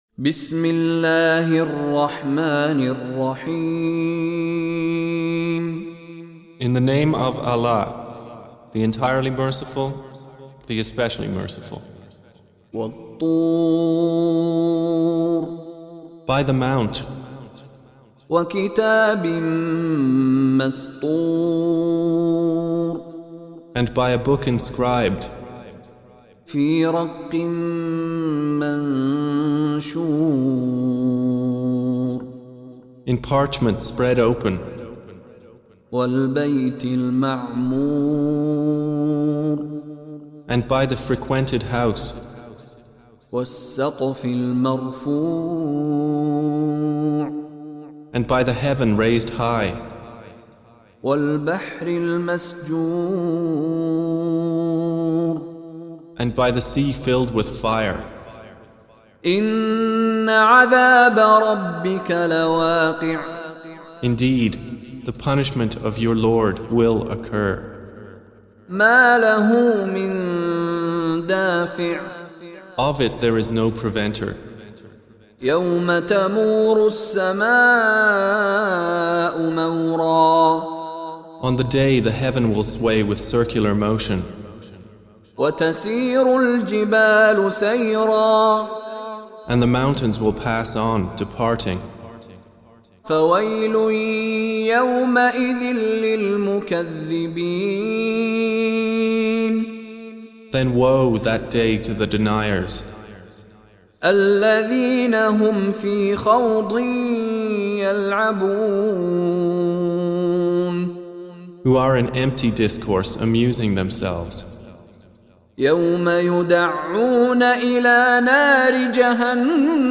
Surah Sequence تتابع السورة Download Surah حمّل السورة Reciting Mutarjamah Translation Audio for 52. Surah At-T�r سورة الطور N.B *Surah Includes Al-Basmalah Reciters Sequents تتابع التلاوات Reciters Repeats تكرار التلاوات